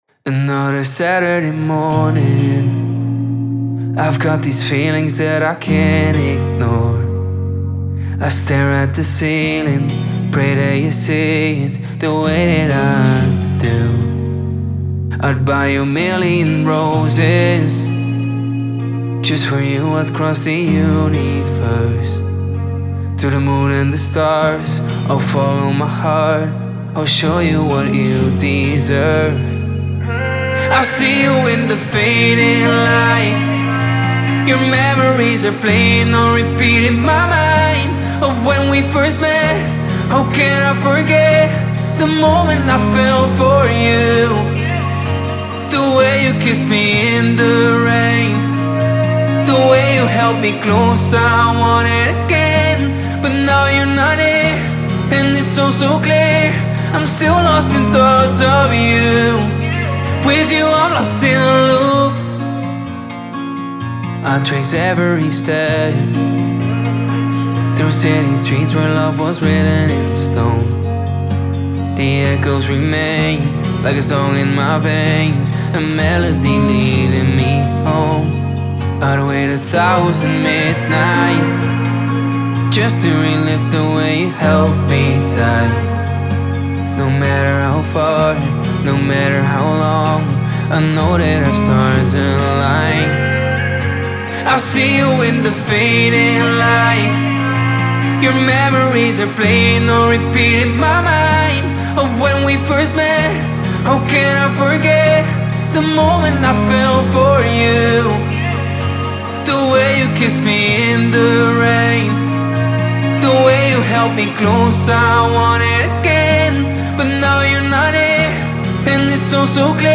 128 Bpm – Bbminor